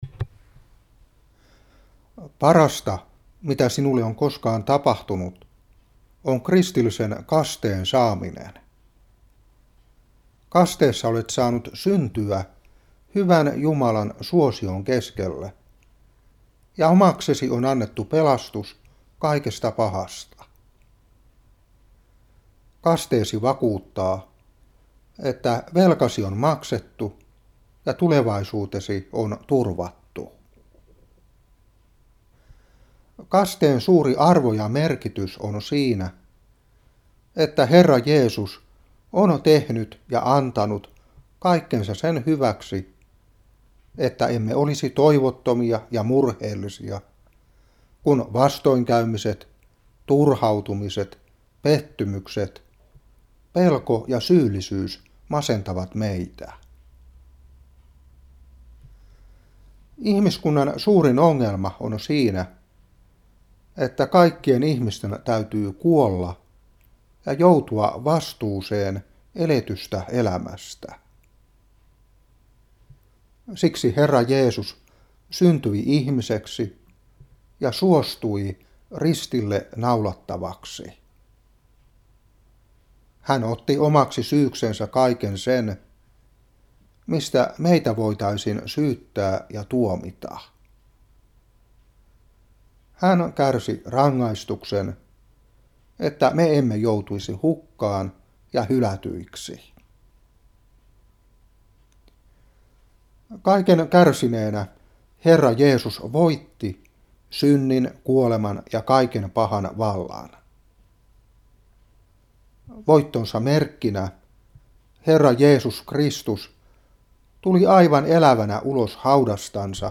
Seurapuhe 2021-3.